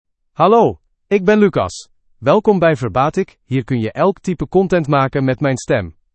MaleDutch (Belgium)
Lucas is a male AI voice for Dutch (Belgium).
Voice sample
Lucas delivers clear pronunciation with authentic Belgium Dutch intonation, making your content sound professionally produced.